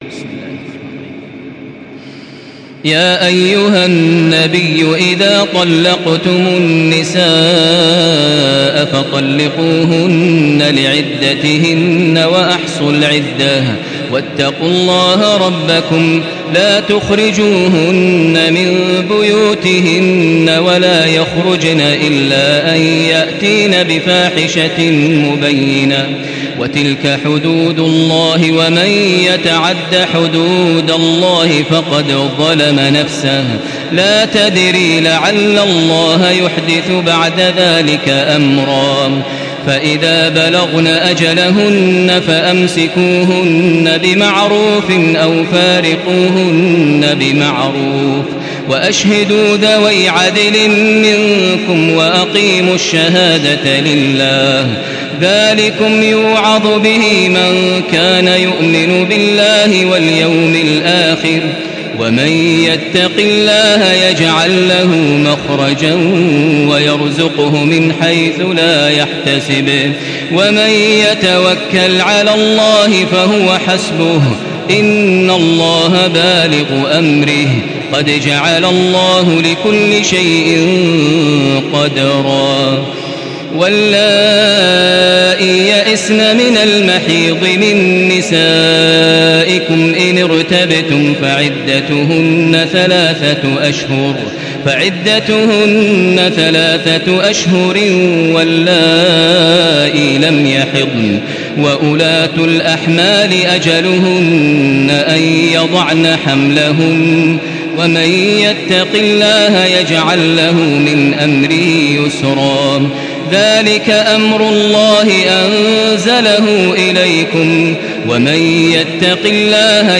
Surah Talak MP3 by Makkah Taraweeh 1435 in Hafs An Asim narration.
Murattal Hafs An Asim